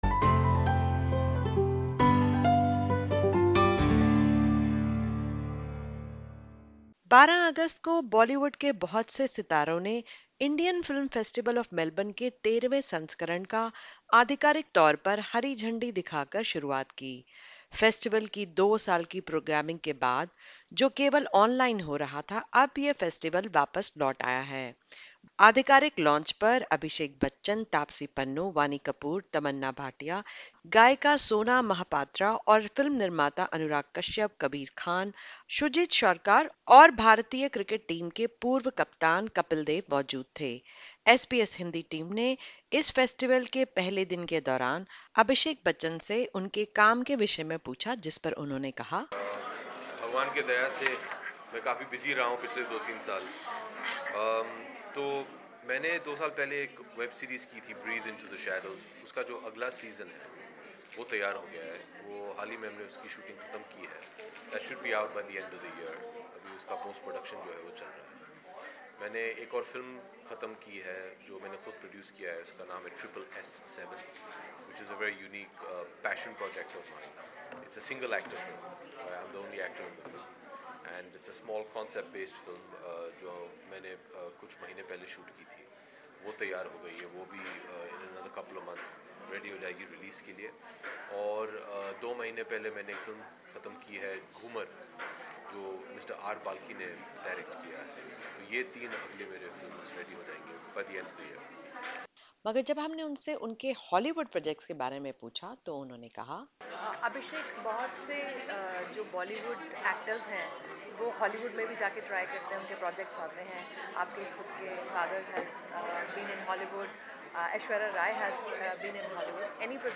Last week, Indian actor Abhishek Bachchan earned the Leadership in Cinema Award at the Indian Film Festival of Melbourne (IFFM) 2022. On the sidelines of the ongoing fest, SBS Hindi spoke to the actor regarding several issues including his film projects, Hollywood, regional films from South India and the influence of the box office on him.